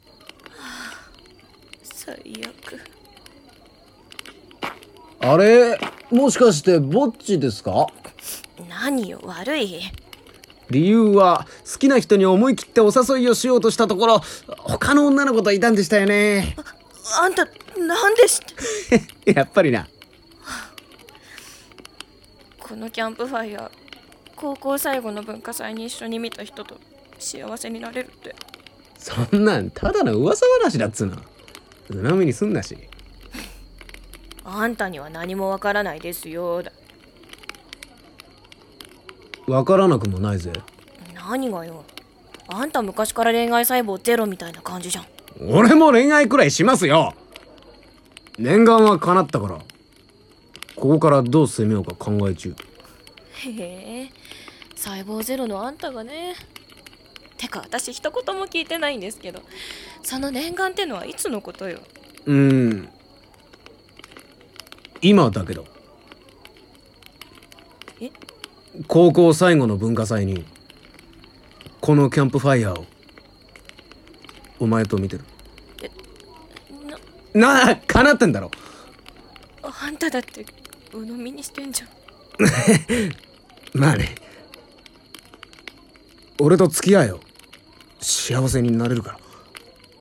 【声劇】 後夜祭